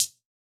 UHH_ElectroHatD_Hit-09.wav